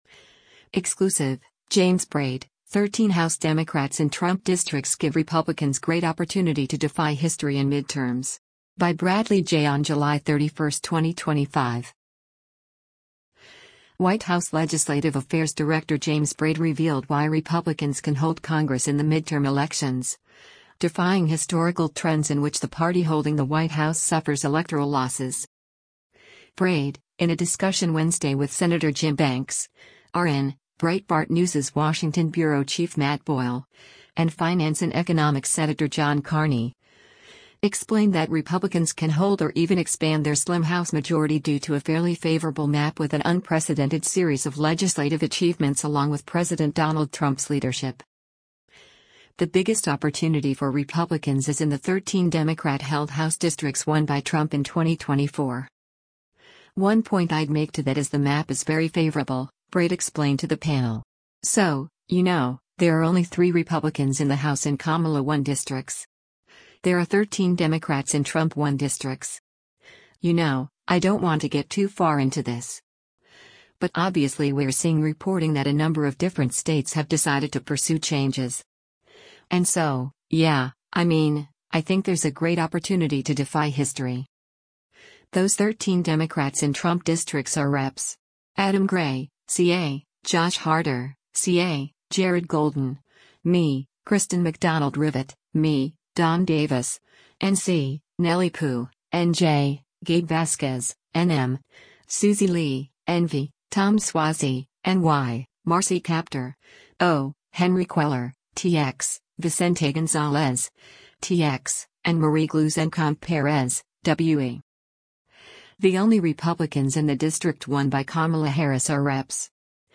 White House Legislative Affairs Director James Braid during a policy discussion with Breit